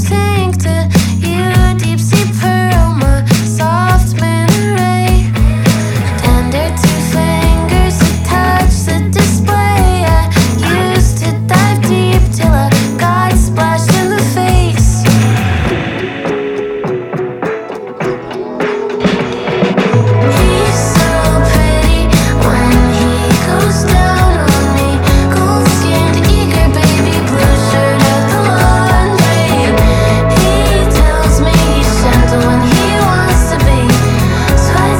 2022-07-01 Жанр: Альтернатива Длительность